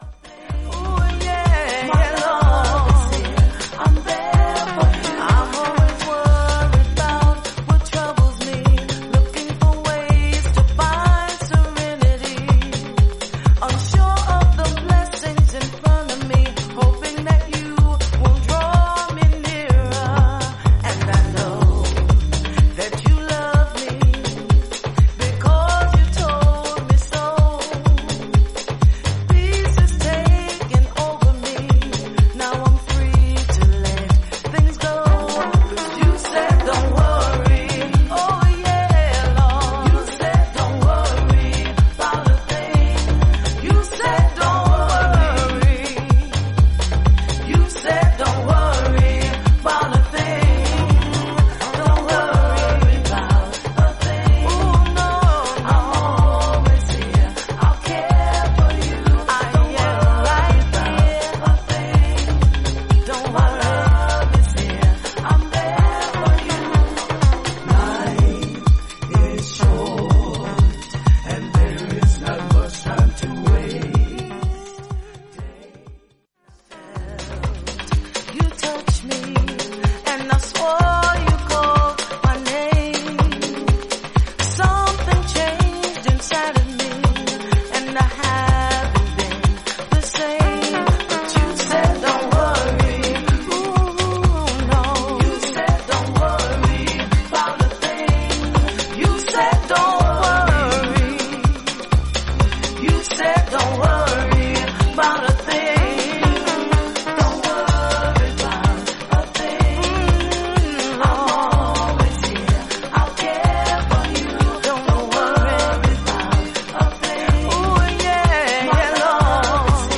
ムーディー且つブラックネス感たっぷりのヴォーカル・ハウス・ナンバー。